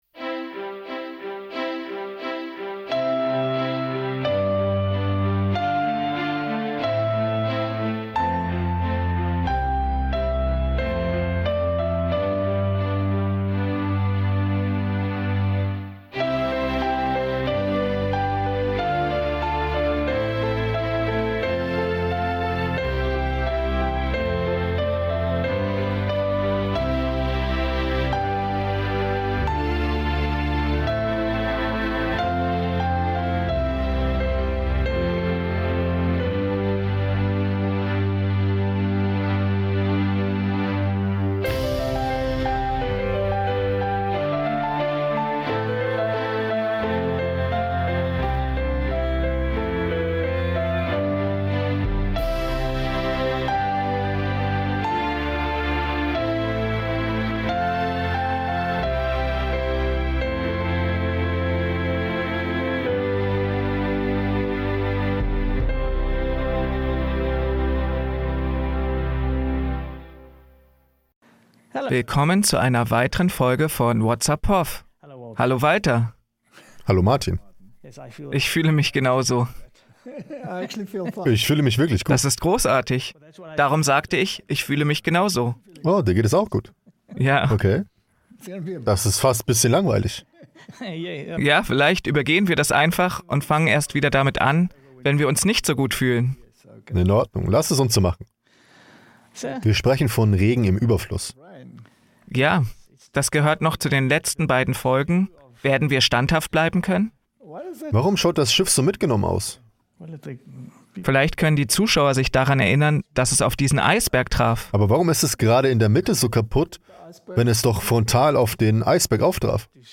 Whats Up, Prof? (Voice Over)